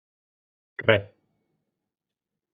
Pronounced as (IPA) [ˈrɛ]